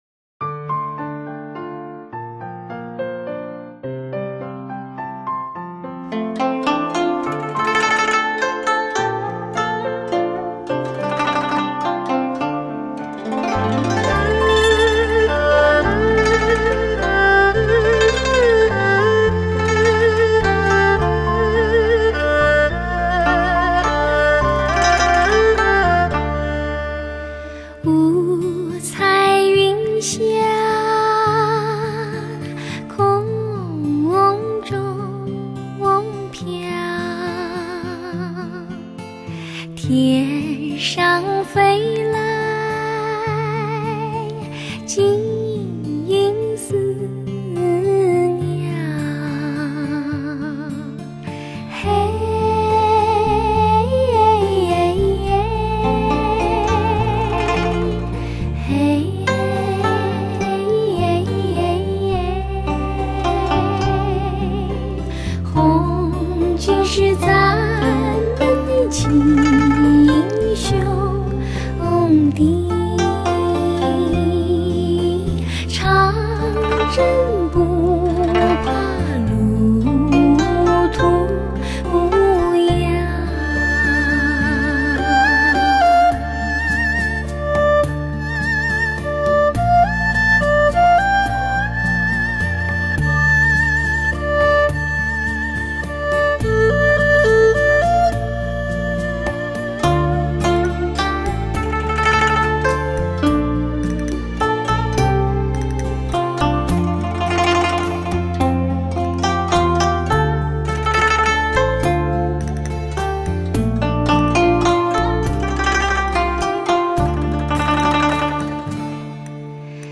极致优美  旋律  音乐层面变化广泛  抑扬顿挫的起伏节奏
各声部平衡度亦是理想至极  配以高素质的录音  每组乐器各就各位
层次分明  一线歌手倾情唱出难忘的时光  乐迷不容错过  经过重新编曲和录音
（试听为低品质wma，下载为320k/mp3）